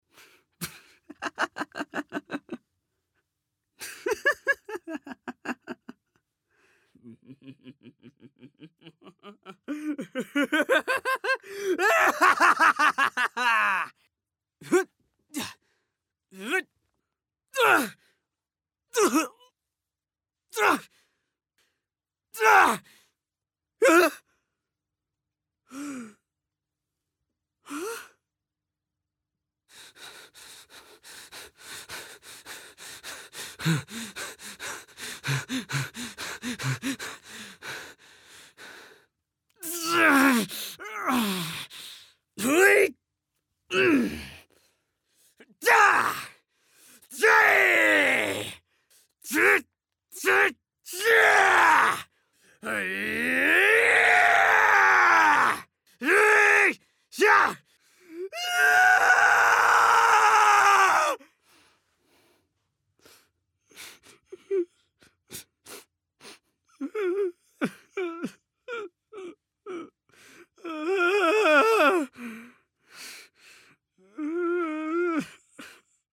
efforts